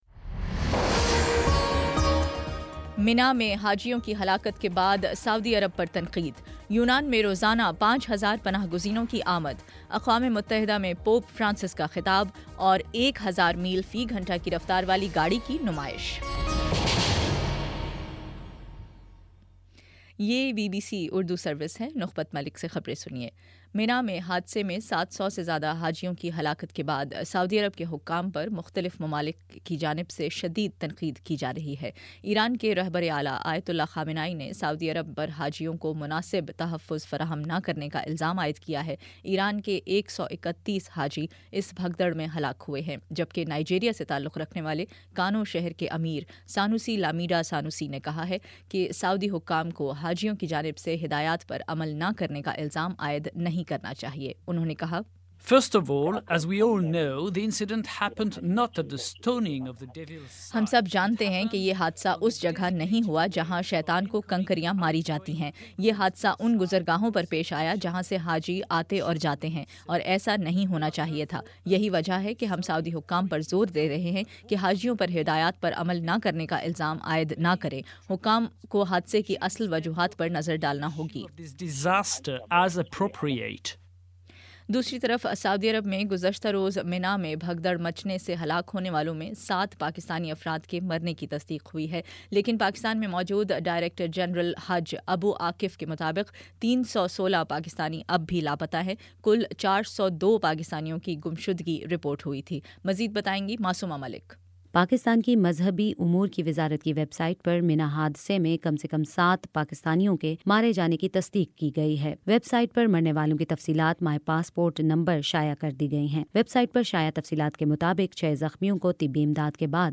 ستمبر25 : شام چھ بجے کا نیوز بُلیٹن